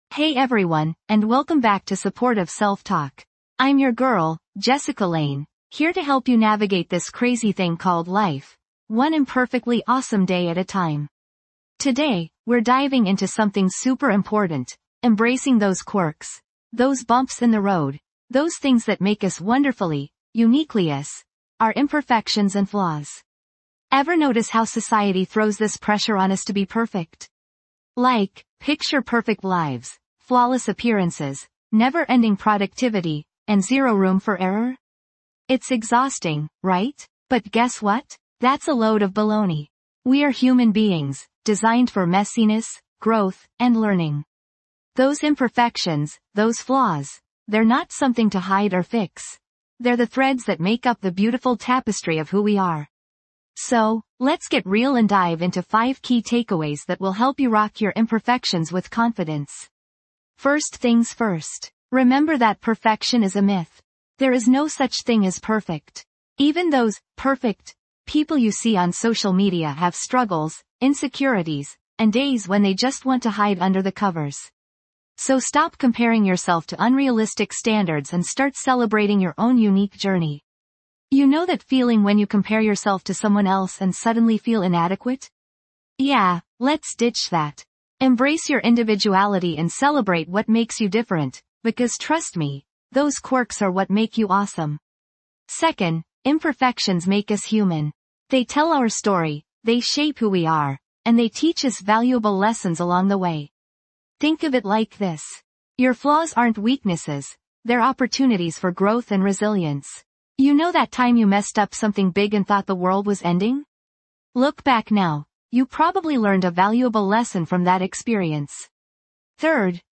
"Supportive Self Talk | Calm Inner Dialogue" guides you on a journey to replace harsh criticism with kindness and compassion. Through guided meditations, mindful exercises, and practical tips, this podcast helps you develop a more positive and empowering relationship with yourself.